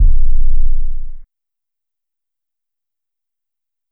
808 (PUPPET).wav